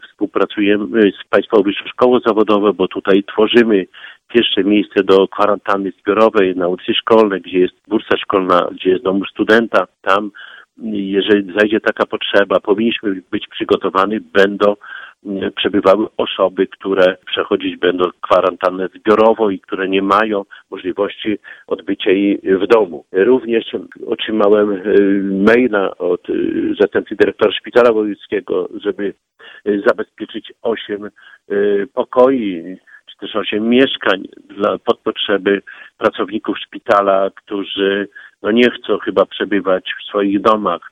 Szczegóły przedstawił Czesław Renkiewicz, prezydent Suwałk.